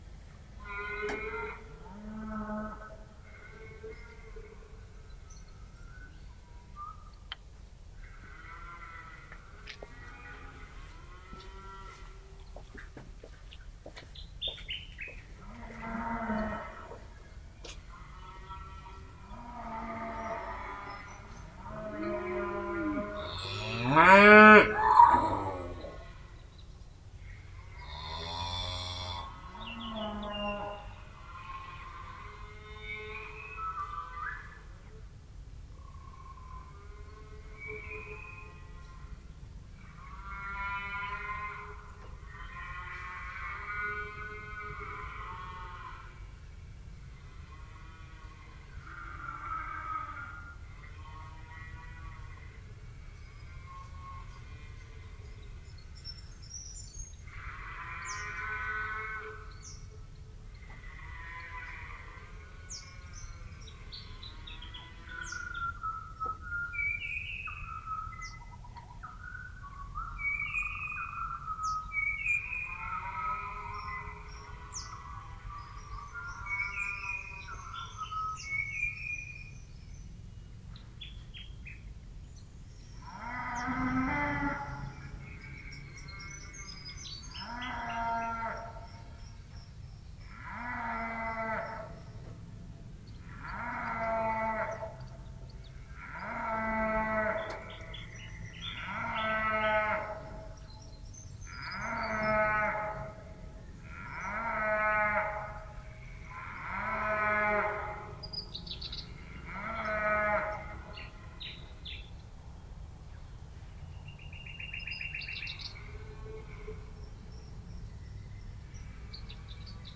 描述：1/3 奶牛，鞭子鸟和笑翠鸟（以及其他）的野外记录。小牛正在从母亲身上消瘦，所以有很多遥远和近乎呻吟。 在带有逼真PZM麦克风的Zoom H4n上录制。 Adobe Audition中的降噪功能
标签： 鸣叫 动物的声音 景观 放牧 农业 养殖 牧场 农业 畜牧业 土地 农村 澳大利亚 热带 国家 农村 一般的噪声 农田
声道立体声